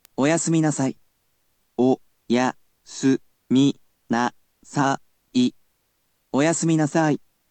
We’ll need to enlist the help of our lovely computer friend, QUIZBO™先生せんせい！
Click on the sound players to ask him to read something for you.